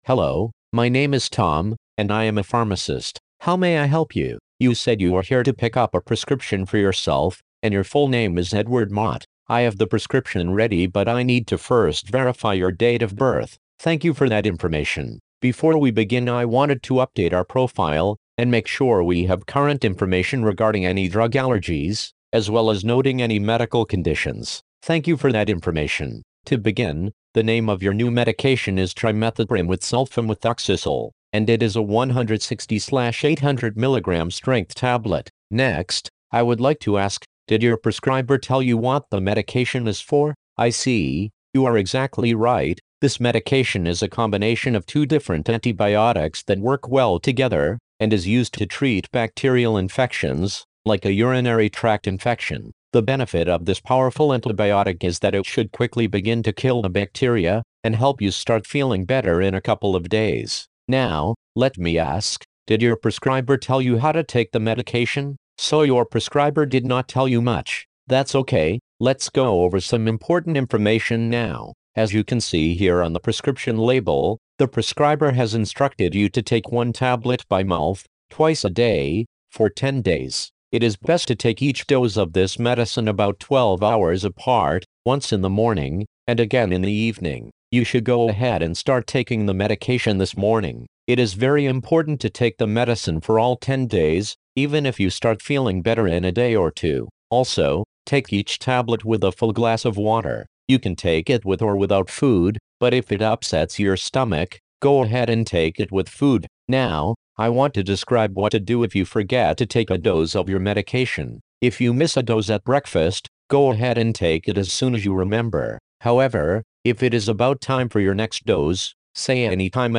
1. Patients were counseled on two common medications following a standardized counseling format.